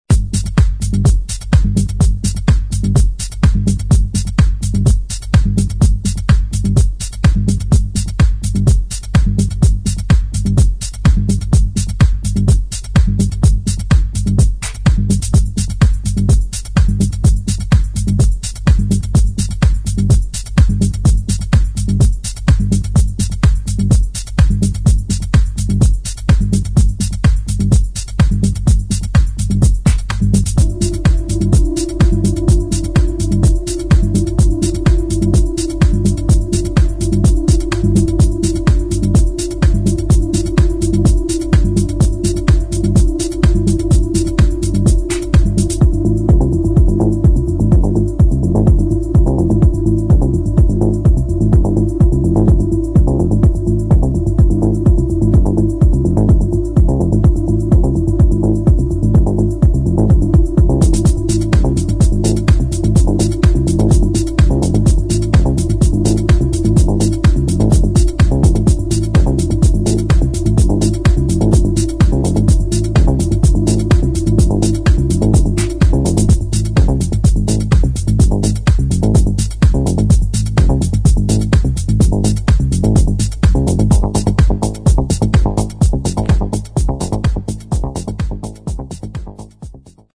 [ TECHNO / HOUSE ]